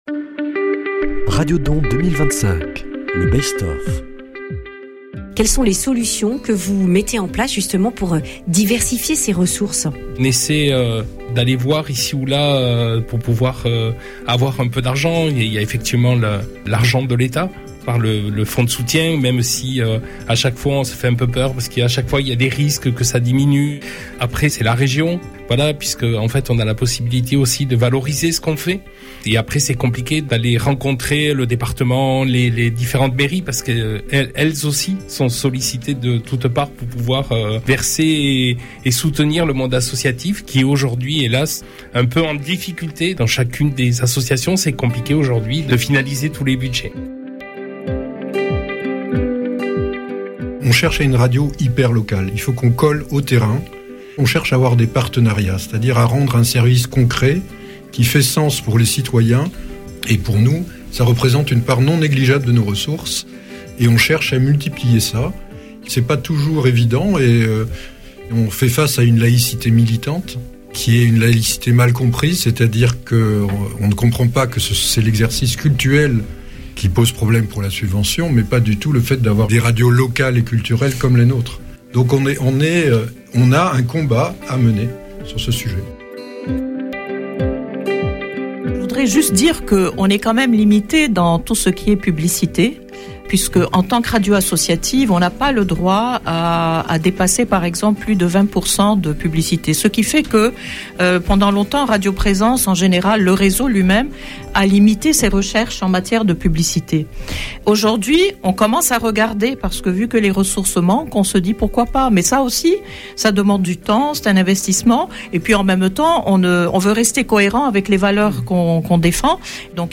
Retrouvez les temps forts des interventions des présidents durant le Radio Don 2025.